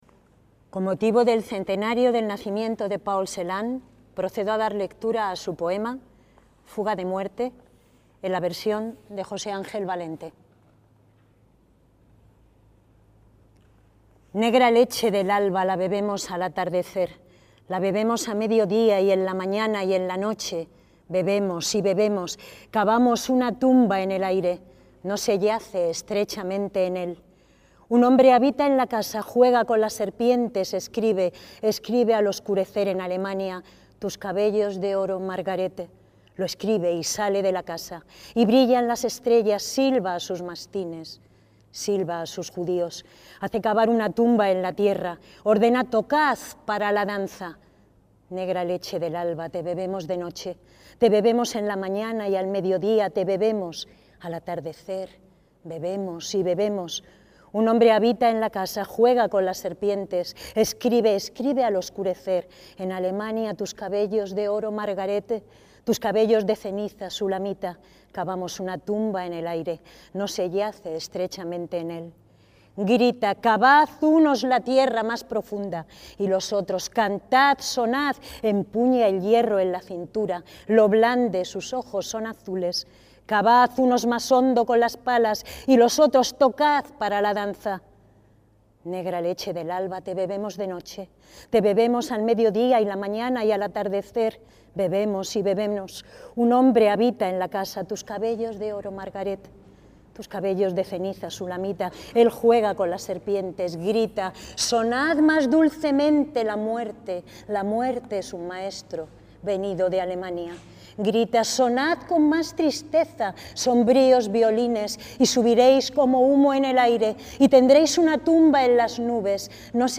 ACTOS EN DIRECTO
organizaron en el Palacio Real de Madrid
Durante el recital, el Emsemble Praeteritum ofreció composiciones de Arnold Schönberg, Felix Mendelssohn y Fritz Kreisler.
Este es el programa del concierto: Arnold Schönberg, Cuarteto de cuerda en Re mayor (1897): Allegro molto, Intermezzo, Andante con moto, Allegro – Presto; Felix Mendelssohn, Cuarteto de cuerda no 6 en fa menor, op. 80: Allegro vivace assa